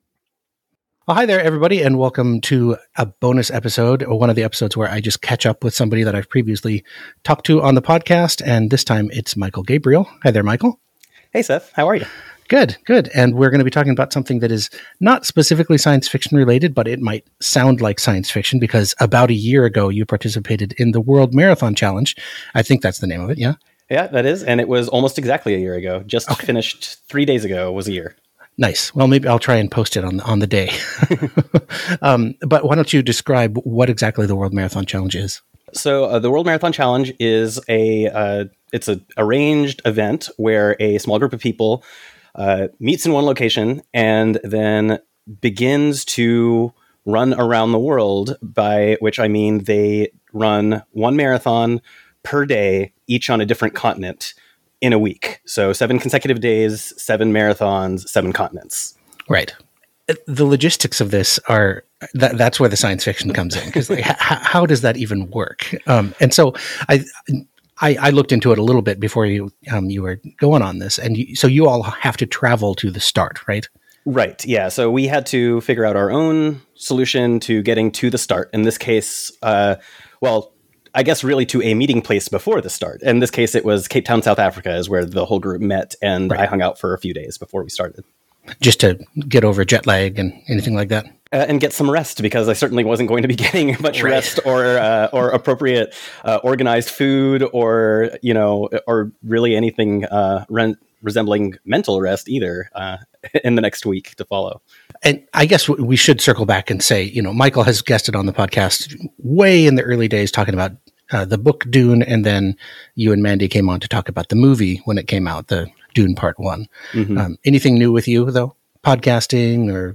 a conversation about the World Marathon Challenge